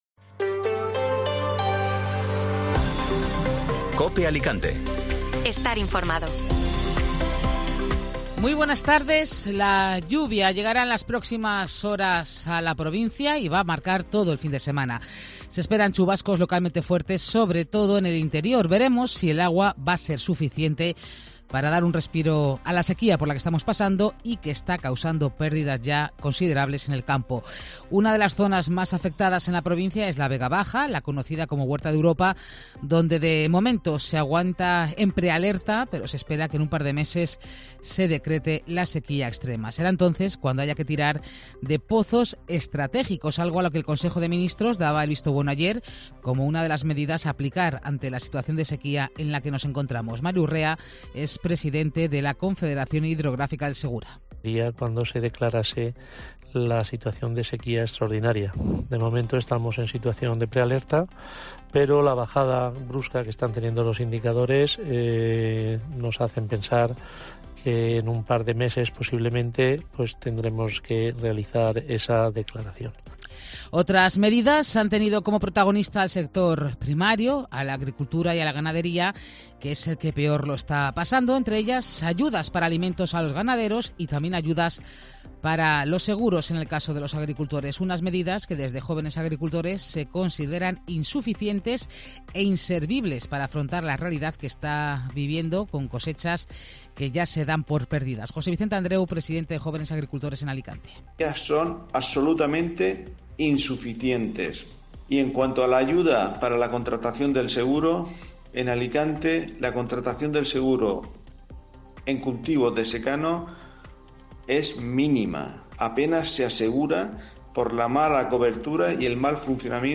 Informativo Mediodía Cope Alicante (Viernes 12 de mayo)